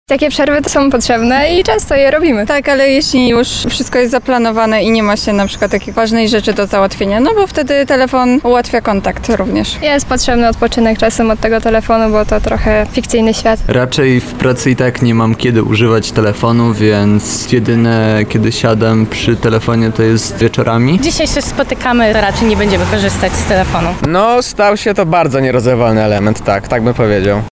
Nasz reporter sprawdził, co na ten temat sądzą lublinianie.
Mieszkańcy